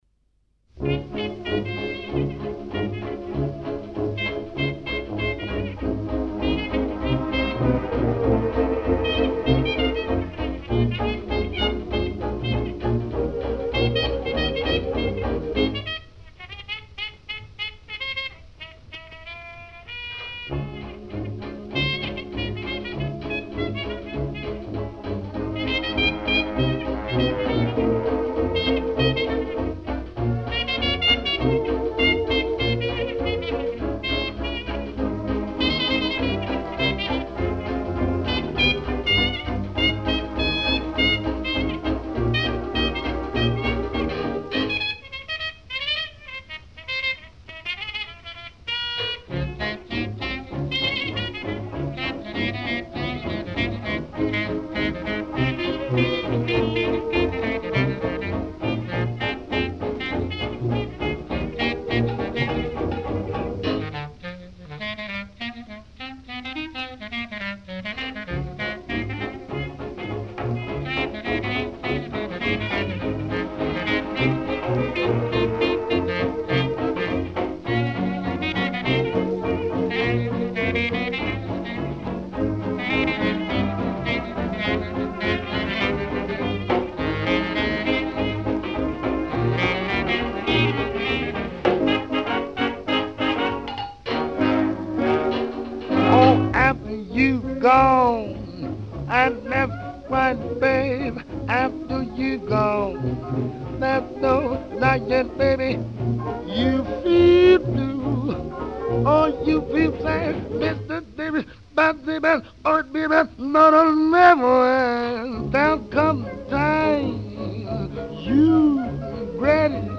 Big Band
• NEW ORLEANS (JAZZ)
• VOCAL (JAZZ)
• Trumpet
• Vocal
• Clarinet
• Piano
• Banjo
• Tuba
• Drums